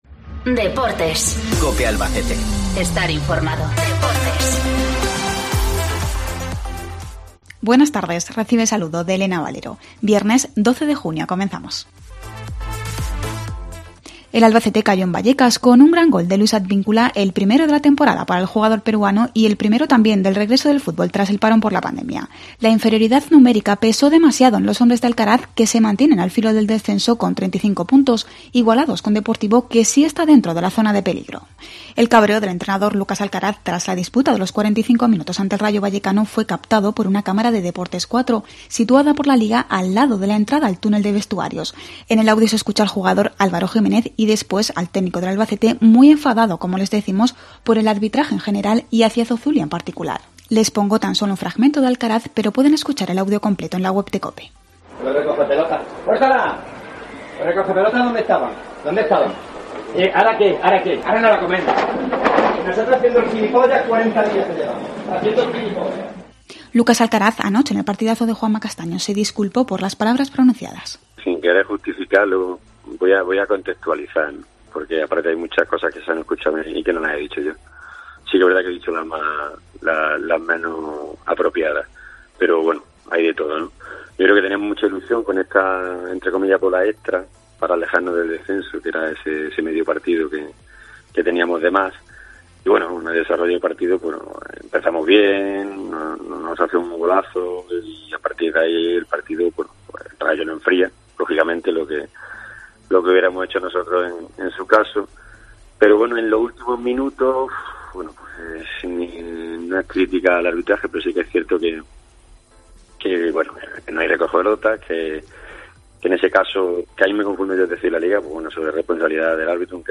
Cabreo del entrenador del Albacete tras la disputa de los 45 minutos ante el Rayo Vallecano, captado por una cámara de Deportes Cuatro. Lucas Alcaraz se disculpa en El Partidazo